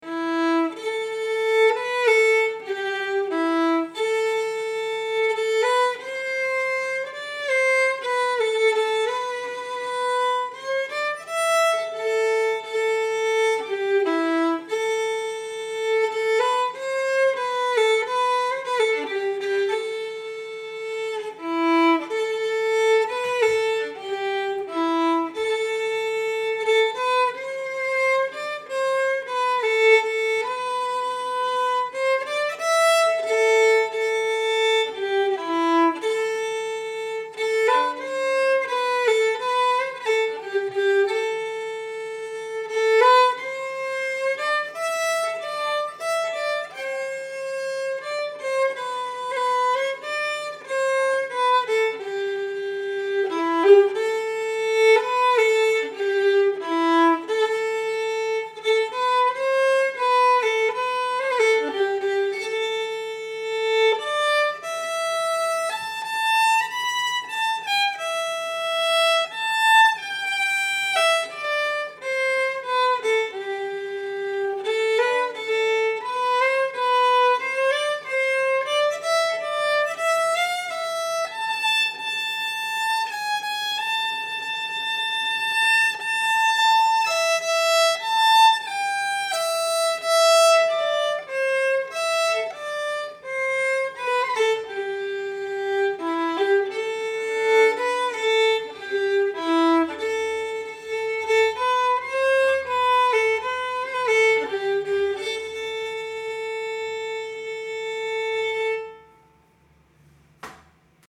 Key: A minor
Form: Air
Fiddle
Henry-B-solo-fiddle-MP3.mp3